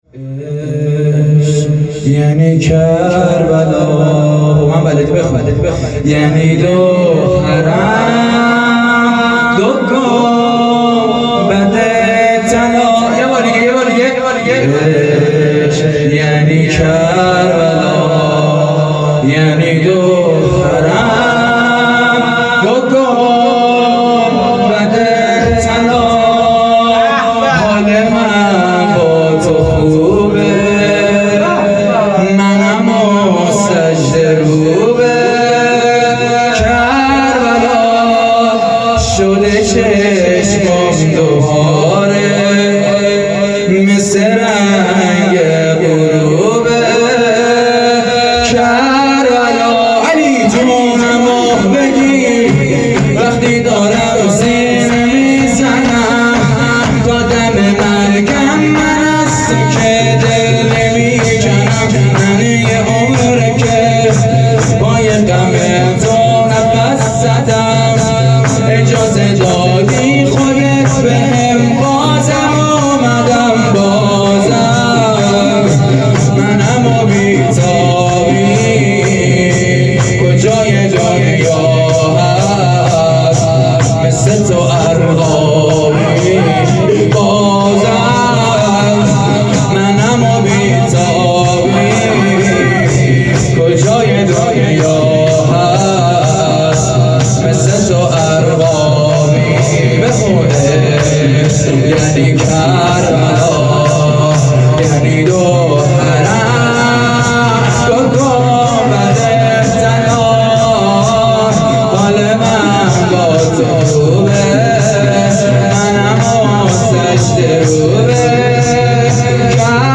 بخش ششم شور
شب پنجم محرم الحرام 1443 | هیئت کاروان حسینی (ع) | ۲۲ مرداد 1400